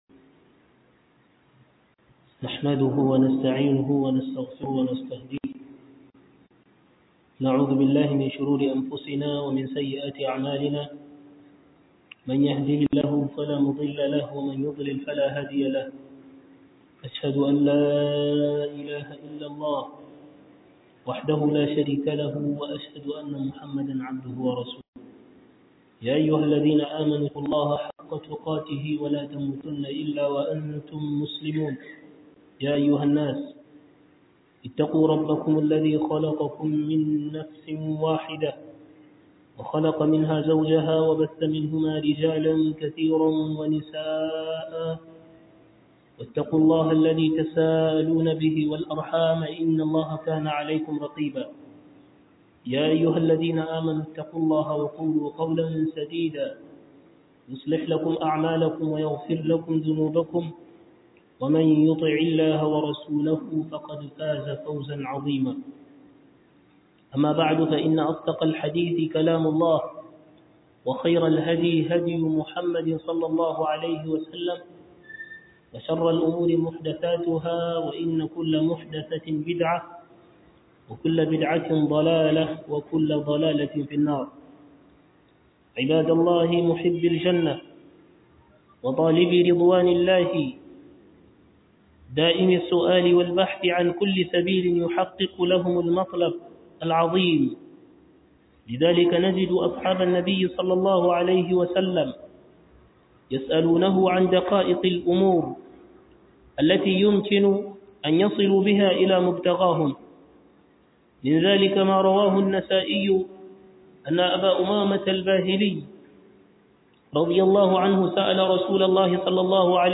Falalar Azumi a Watan Muharram - Huduba